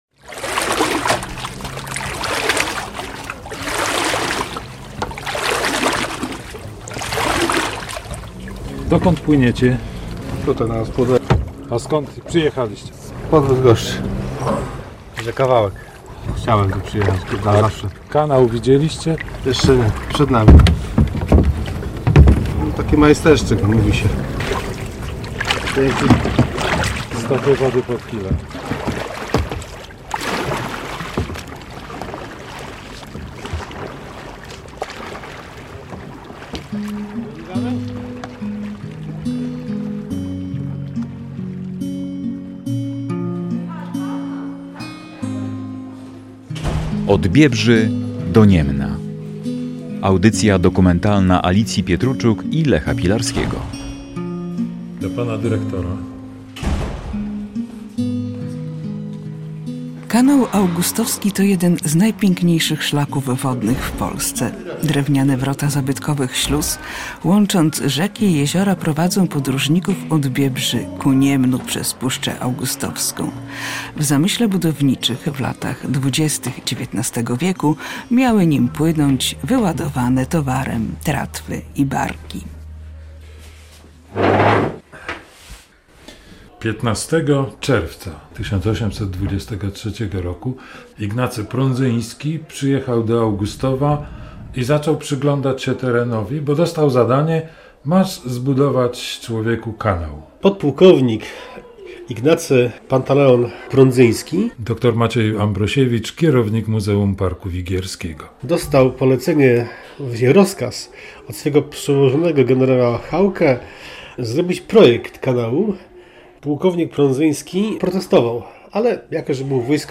Radio Białystok | Reportaż